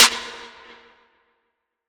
DDK1 SNARE 5.wav